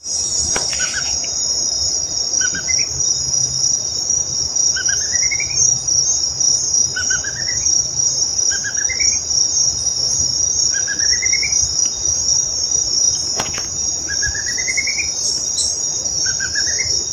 Choca Amarilla (Dysithamnus mentalis)
Editado con Audacity
Nombre en inglés: Plain Antvireo
Localidad o área protegida: Parque Nacional Iguazú
Condición: Silvestre
Certeza: Vocalización Grabada